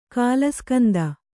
♪ kālaskanda